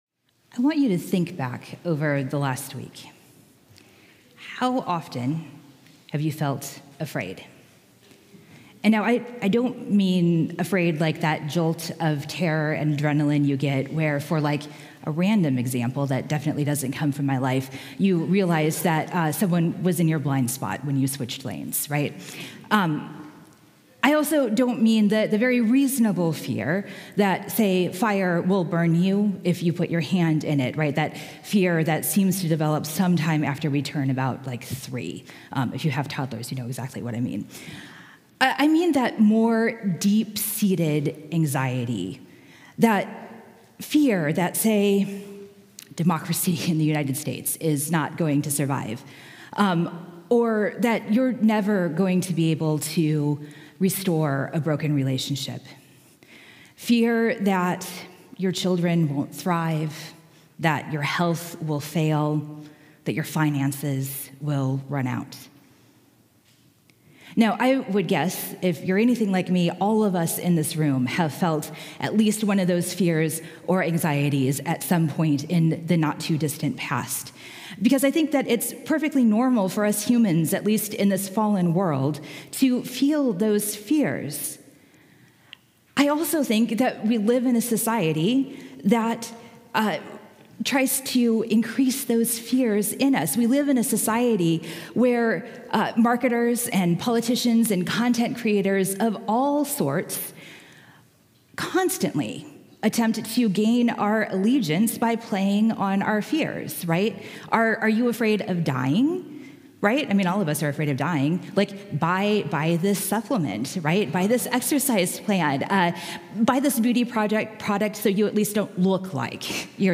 Sermon -